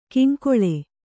♪ kiŋkoḷe